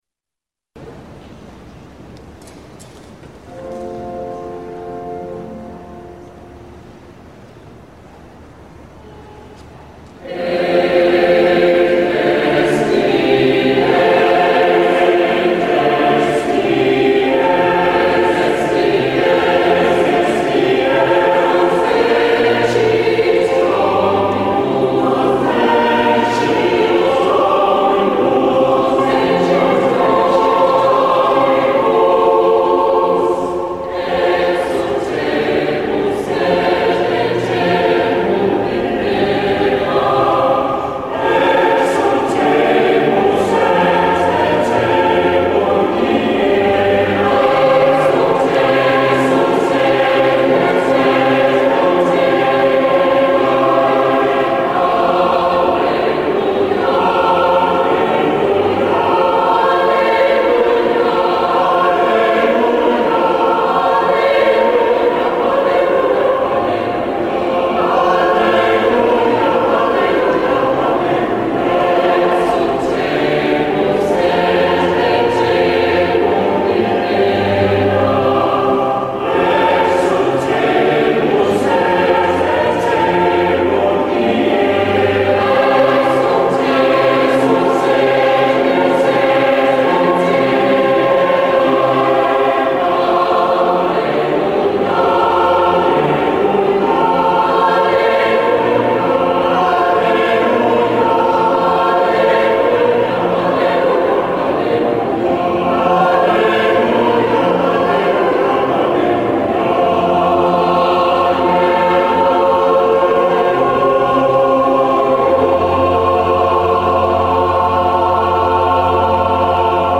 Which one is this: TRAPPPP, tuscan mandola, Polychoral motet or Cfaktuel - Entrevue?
Polychoral motet